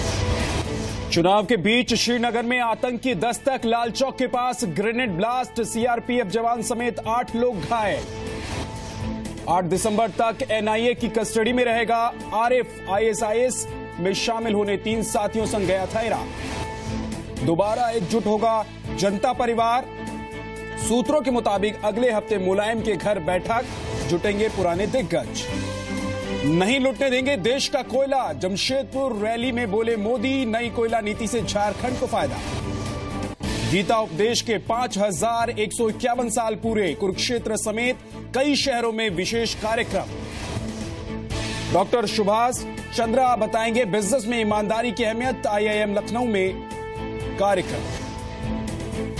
Top news headlines at 4 pm